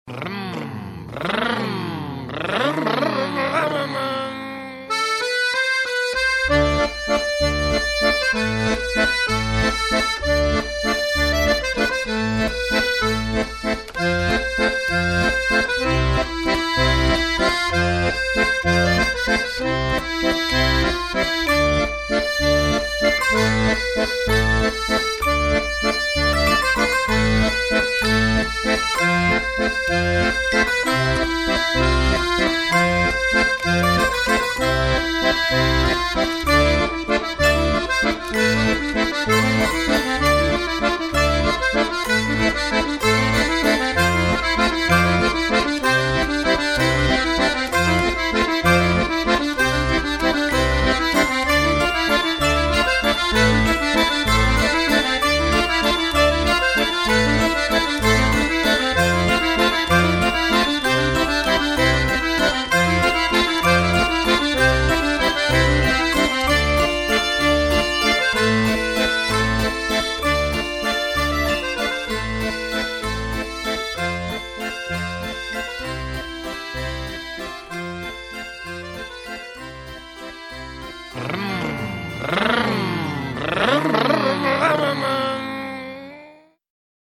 Замечательно!.....брррум-брррруммм вообще отпад:))))